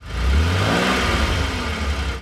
rev_out2.wav